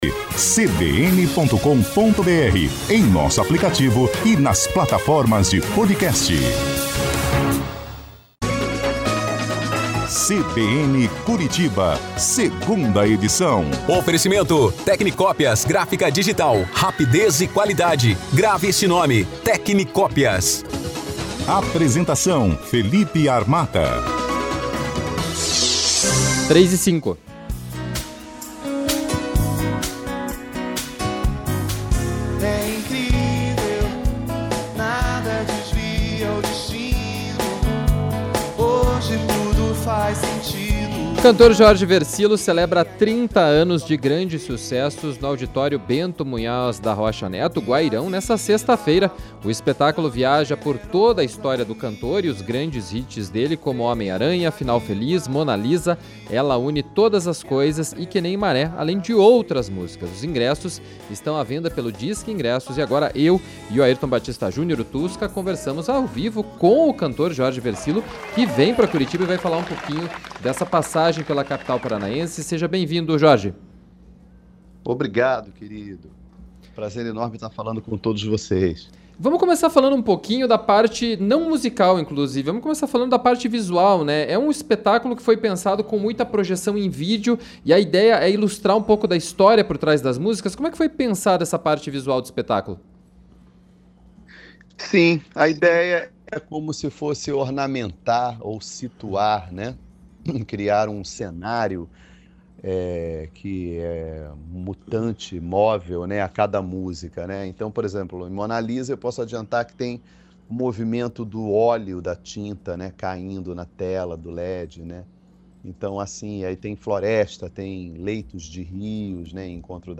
conversaram com o cantor Jorge Vercillo.